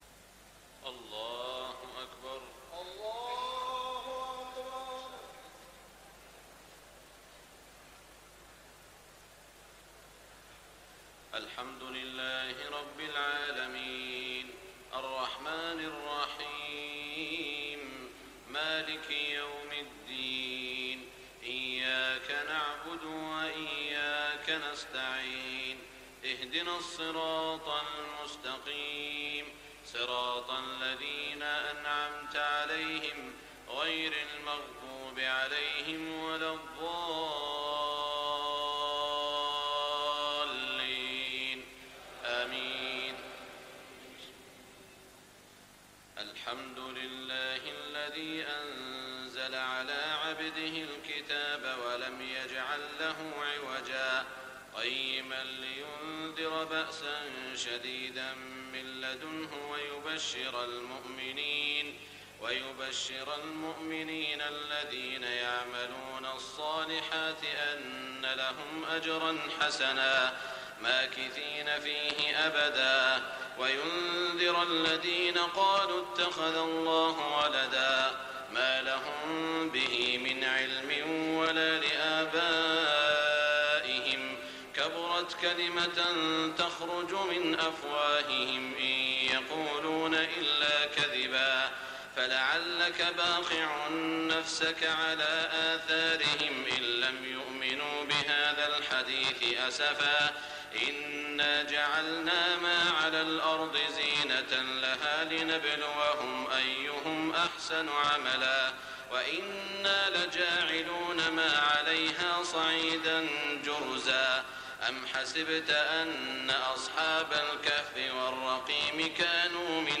تراويح ليلة 30 رمضان 1422هـ من سورة الكهف (1-82) Taraweeh 30 st night Ramadan 1422H from Surah Al-Kahf > تراويح الحرم المكي عام 1422 🕋 > التراويح - تلاوات الحرمين